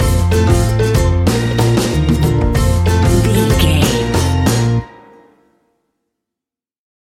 A warm and fun piece of calypso reggae style fusion music.
That perfect carribean calypso sound!
Uplifting
Ionian/Major
B♭
steelpan
drums
percussion
bass
brass
guitar